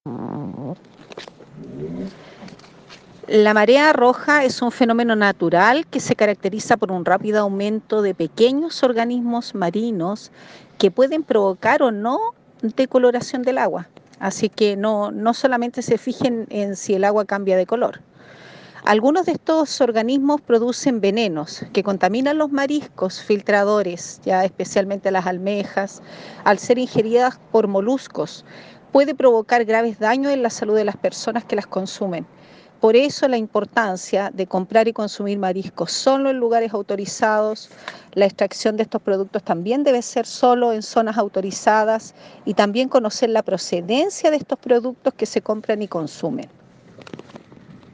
Seremi-de-Salud-Paola-Salas.mp3